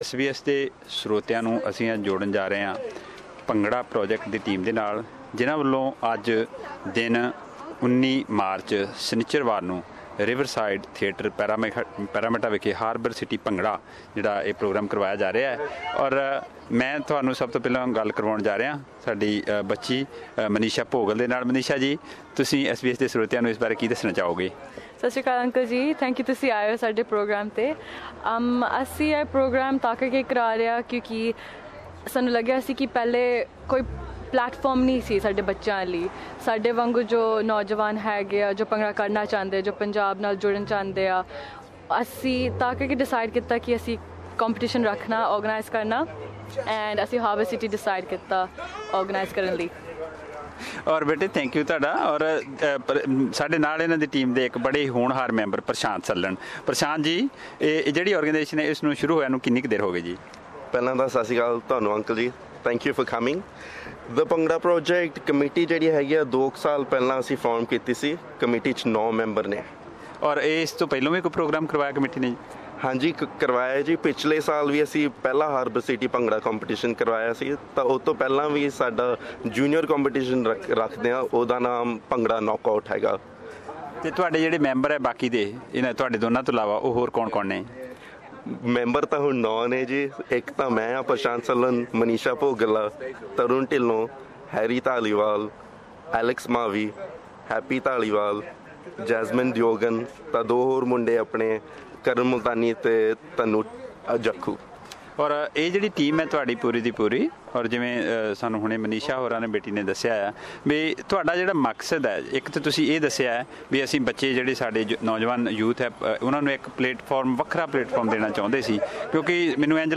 Here is the full conversation.